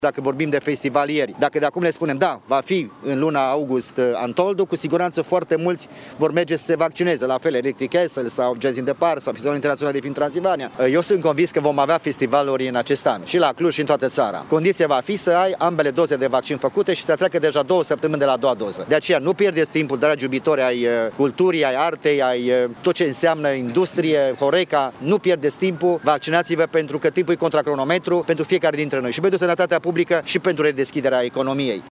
Emil Boc a precizat că e nevoie ca Guvernul să comunice clar că în această vară va fi permisă organizarea festivalurilor mari precum Untold. Numai așa îi vor impulsiona pe cei care cumpără bilet să se vaccineze, spune primarul Clujului: